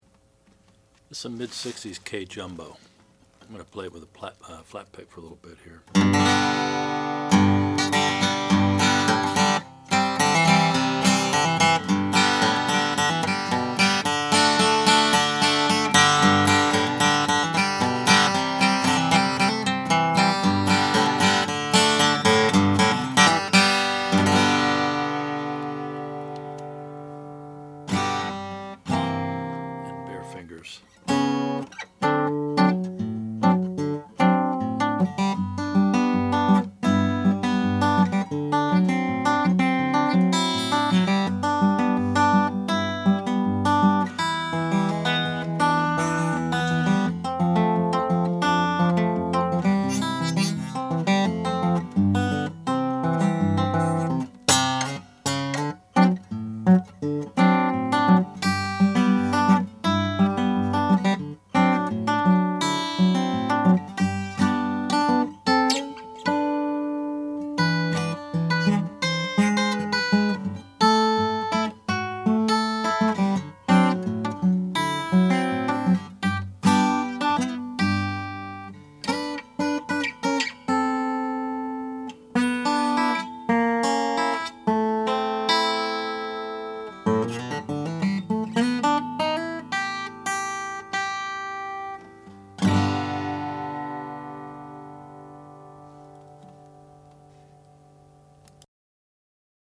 ~1966 Kay K27 Jumbo Natural
The sound produced is quite pleasing, with a deep boom in the bass and an articulate midrange and treble. Flat picked, it's pretty assertive, but is also fun to finger pick and feel the thump with minimal picking effort.